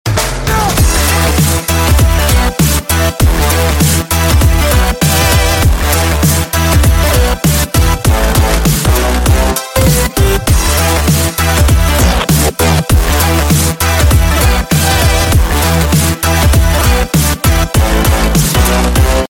Громкие Рингтоны С Басами » # Рингтоны Без Слов
Рингтоны Электроника